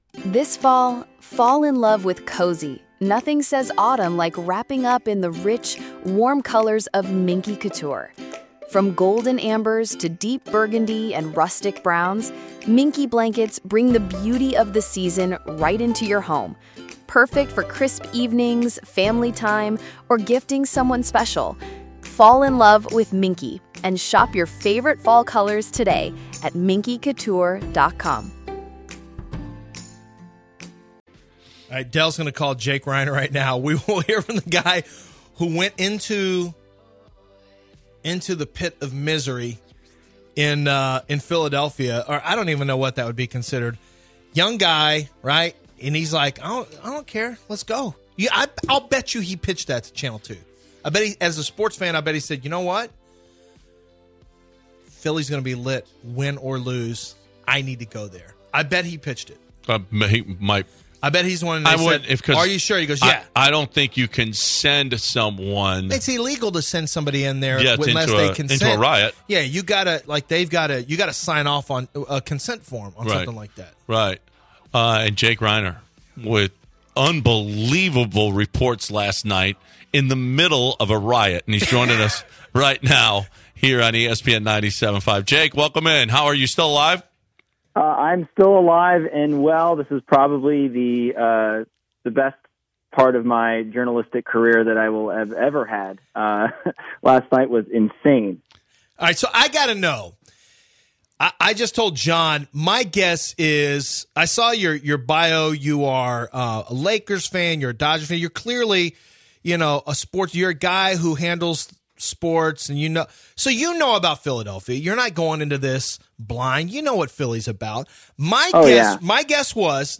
Live from Philadelphia